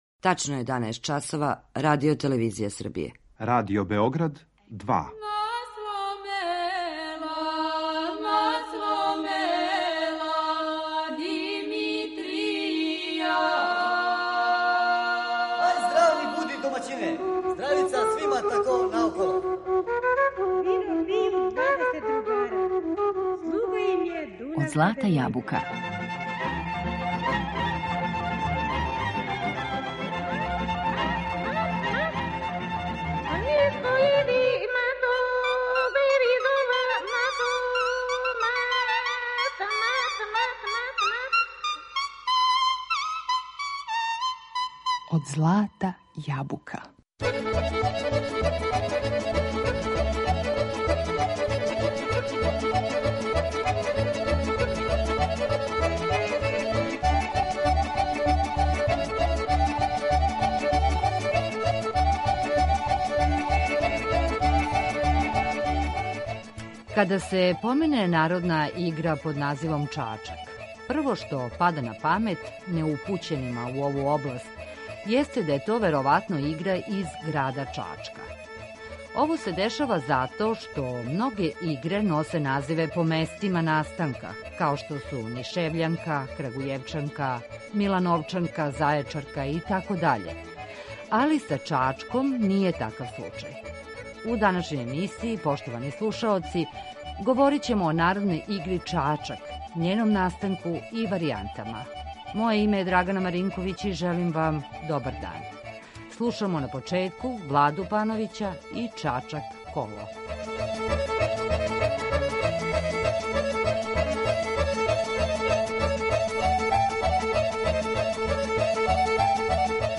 Данашњу причу у емисији Од злата јабука пропратићемо богатим примерима играчких и музичких варијаната ове игре, које су забележене у разним крајевима Србије, под називима бањски чачак, сврљишки, нишки, србијански, ситан чачак, крупан итд.